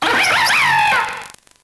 Cri de Rhinolove dans Pokémon Noir et Blanc.